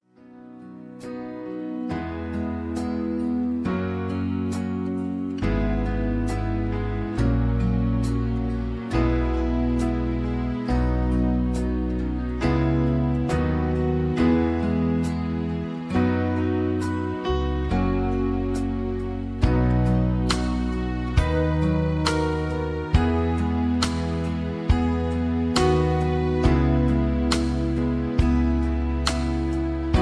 (Key-B) Karaoke MP3 Backing Tracks
Just Plain & Simply "GREAT MUSIC" (No Lyrics).
karaoke mp3 tracks